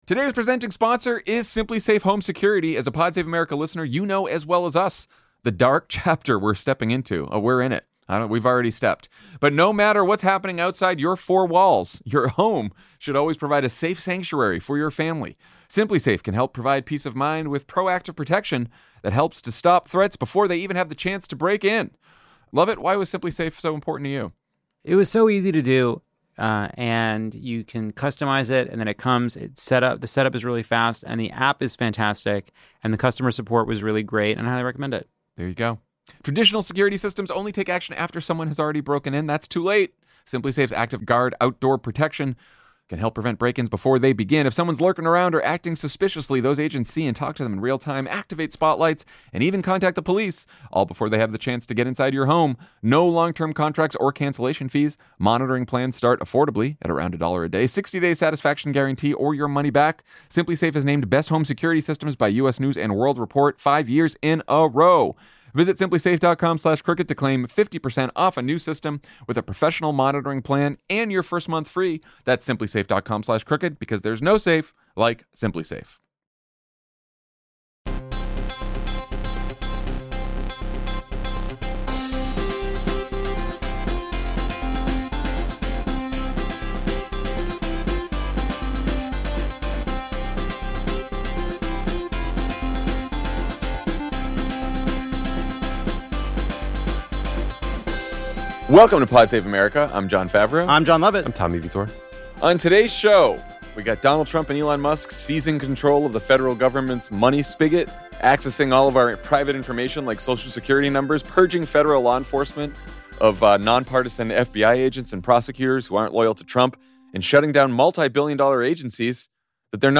Jon, Lovett, and Tommy break down all the latest, and Lovett speaks with former Obama and Biden economic adviser Brian Deese about just how bad things could get if a real trade war kicks off over the next four years.